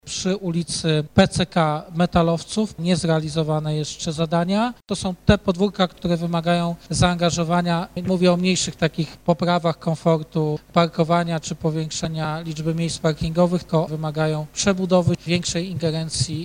To jedne z zadań podwórkowych, które wymagają większej przebudowy niż sama modernizacja parkingów. Mówił o tym prezydent Stalowej Woli Lucjusz Nadbereżny: